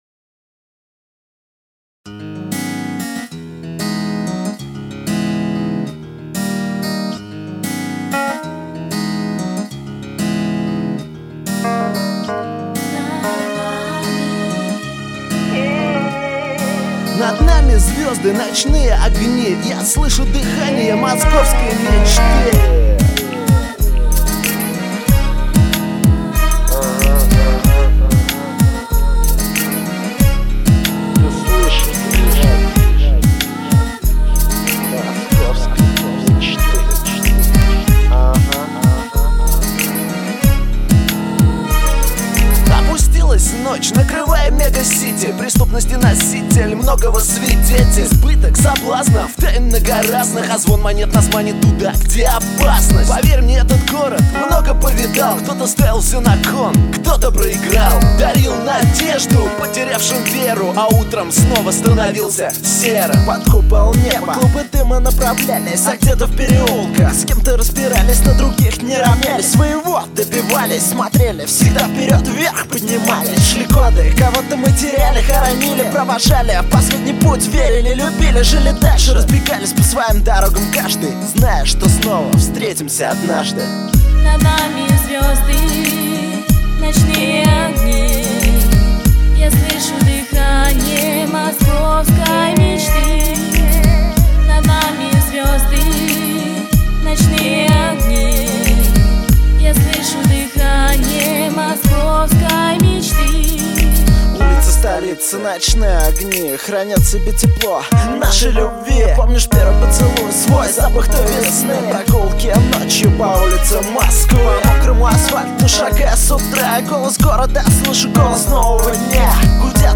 Категория: РэпЧина